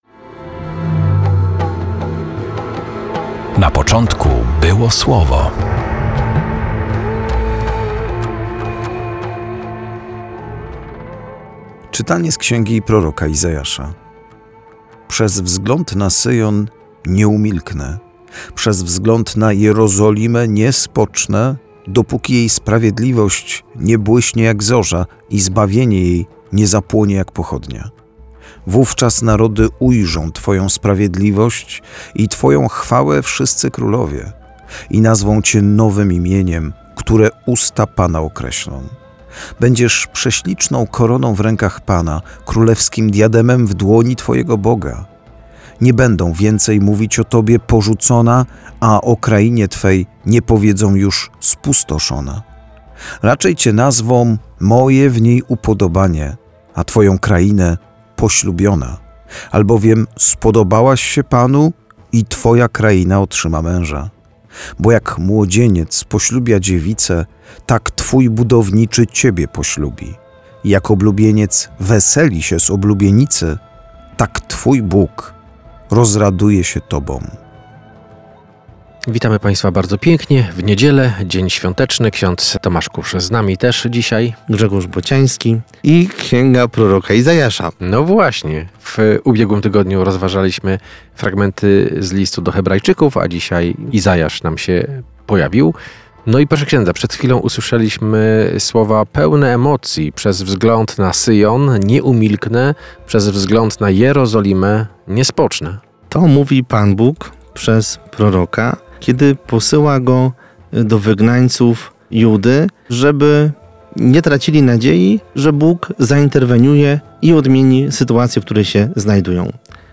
Perły ukryte w liturgii słowa odkrywają księża: